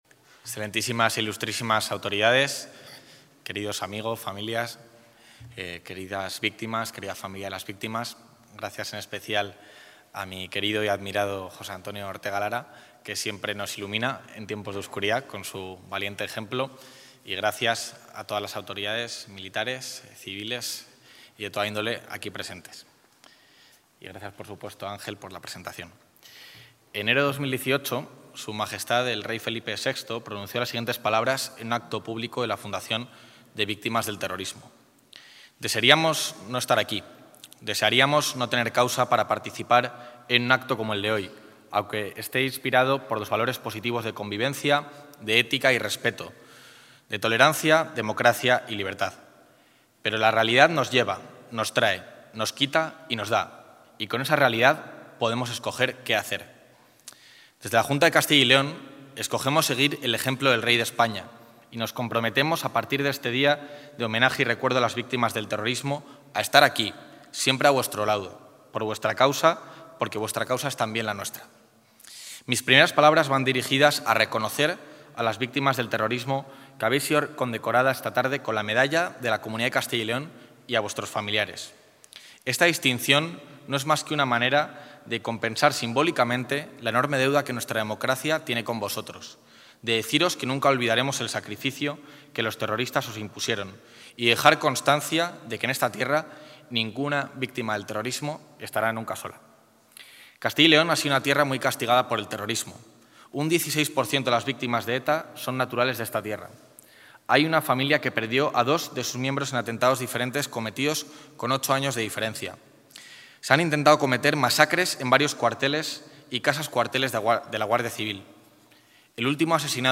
Intervención del vicepresidente de la Junta.
Juan García-Gallardo ha anunciado que la Junta elaborará un censo voluntario de víctimas del terrorismo de Castilla y León para poder honrarlas en su sepultura y recuperar de este modo la memoria individual de cada una de ellas. El vicepresidente del Gobierno autonómico ha hecho este anuncio en el acto de entrega de medallas con motivo del ‘Día de Recuerdo y Homenaje a las Víctimas del Terrorismo’ celebrado esta tarde en la Sala Fray Pío del Monasterio de Nuestra Señora de Prado en Valladolid.